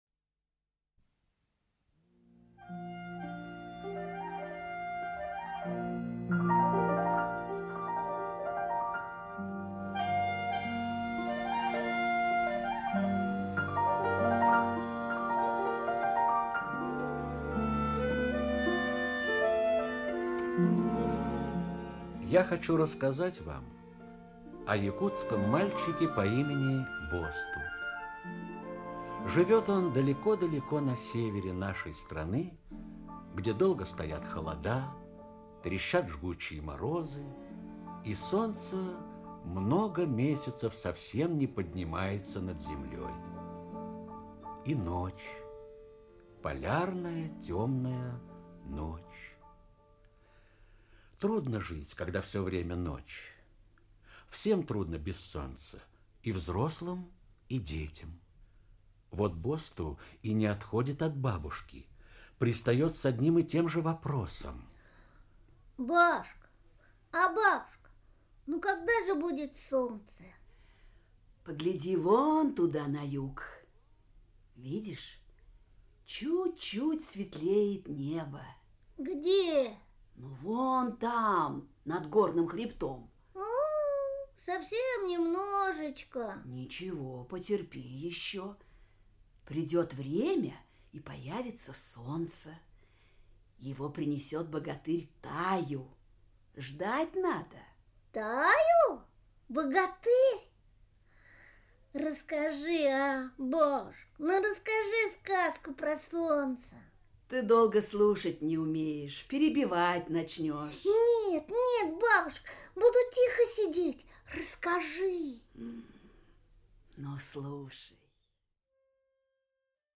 Аудиокнига Таю принесёт солнце | Библиотека аудиокниг
Aудиокнига Таю принесёт солнце Автор Иван Егоров Читает аудиокнигу Актерский коллектив.